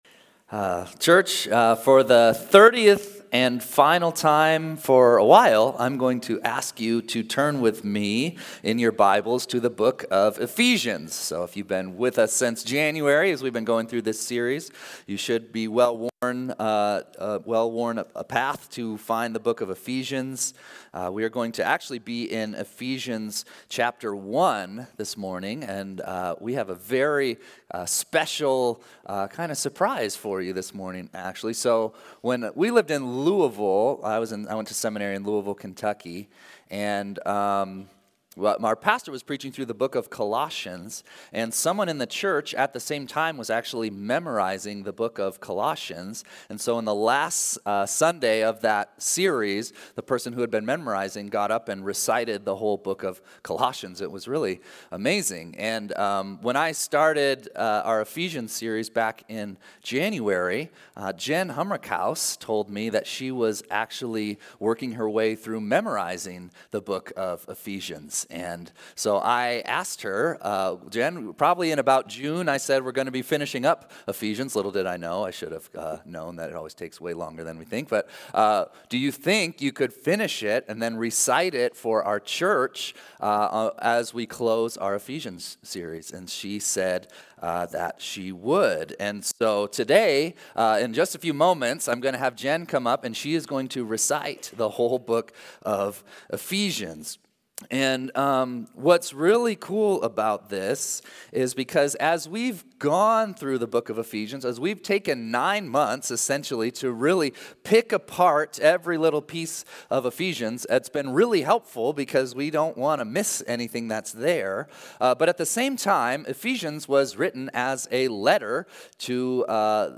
Sunday-Service-9-15-24.mp3